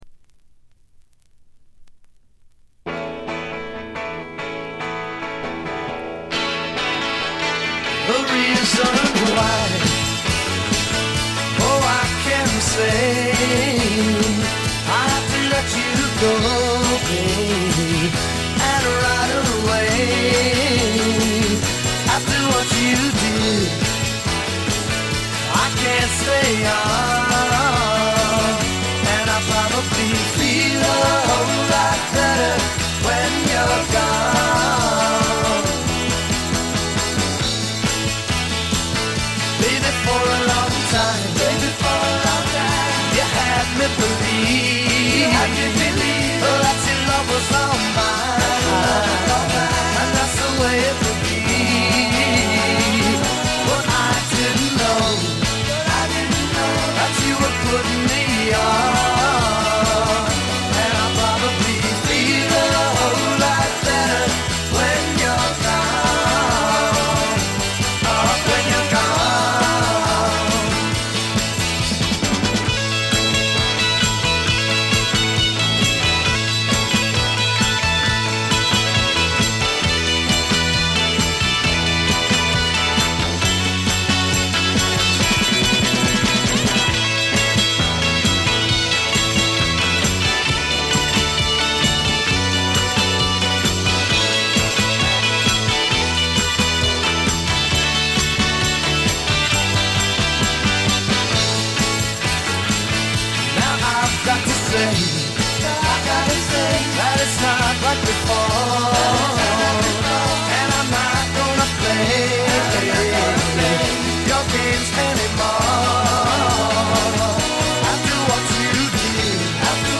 バックグラウンドノイズは出てはいますが気にならないレベルです。
モノラル。
試聴曲は現品からの取り込み音源です。